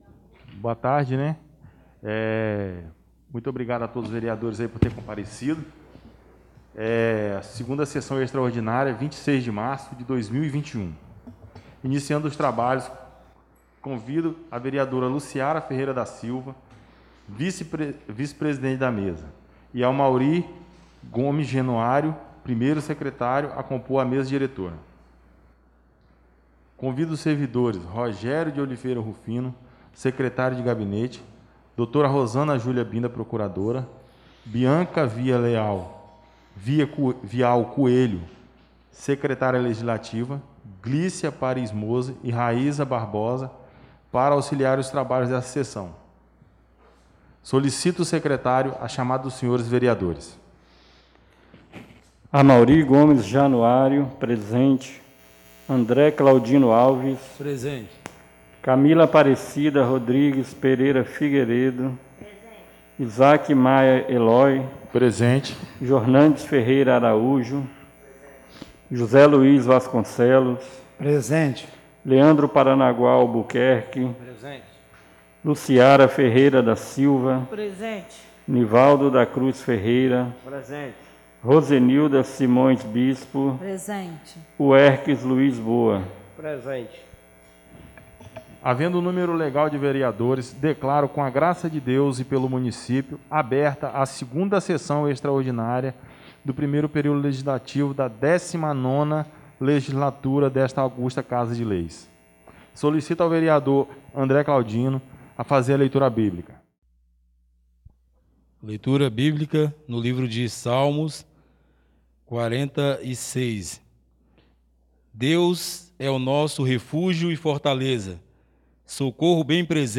2ª Sessão Extraordinária do dia 26 de março de 2021